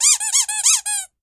Звук писка игрушки